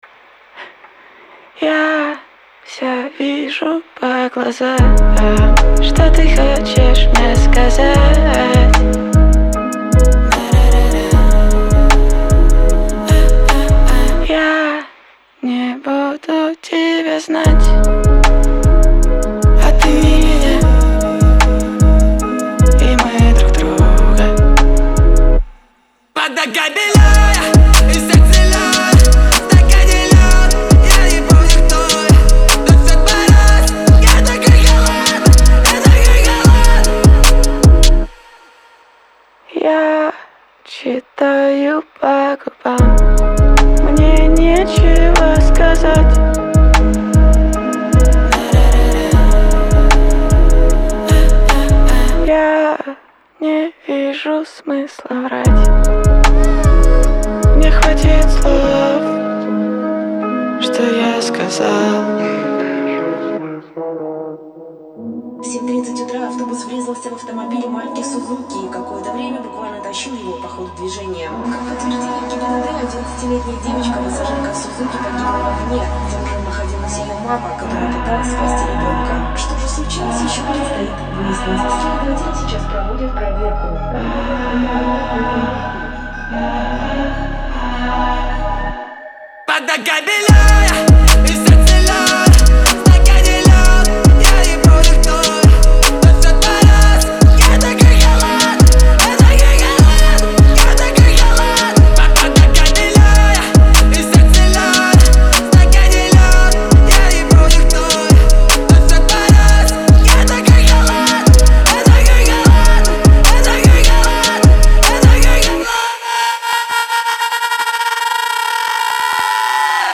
Русский Поп